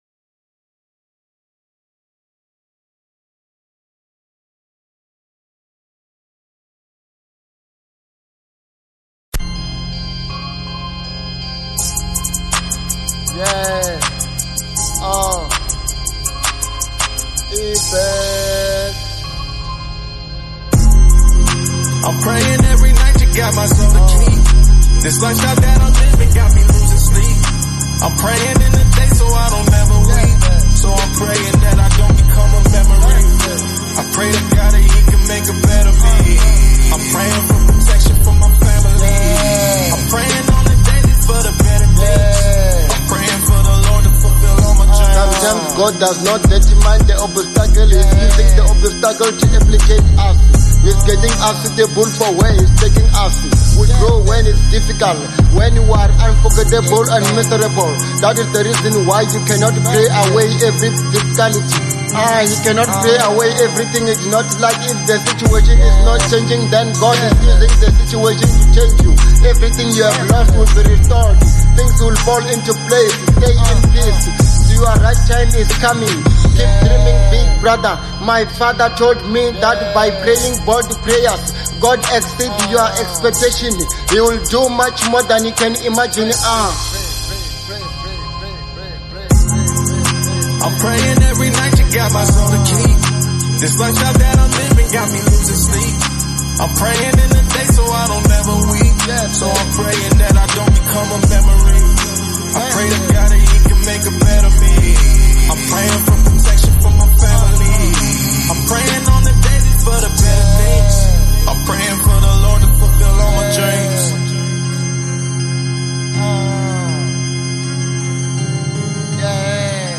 02:12 Genre : Hip Hop Size